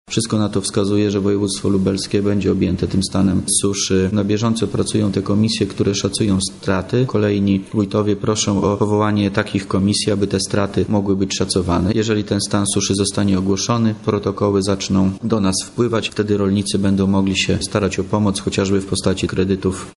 mówi Wojewoda Lubelski Wojciech Wilk